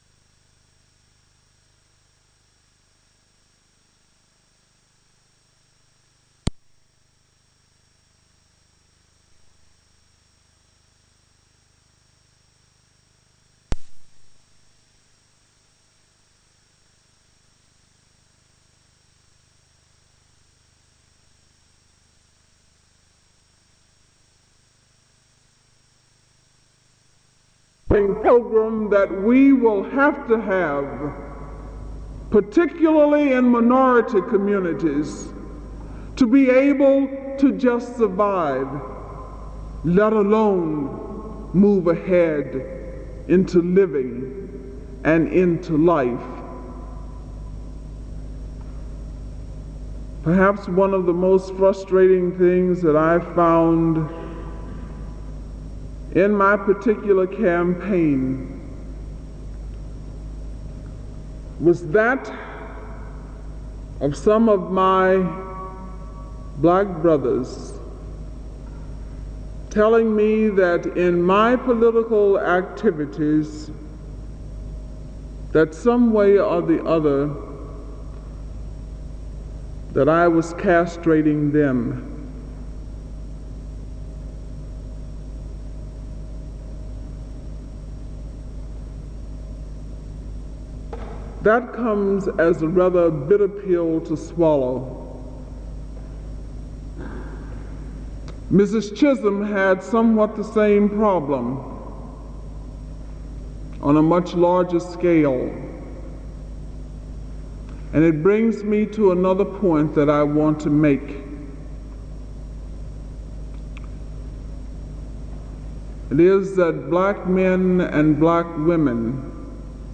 Creator Tuskegee University Chapel
Medium Audiotapes-Reel-To-Reel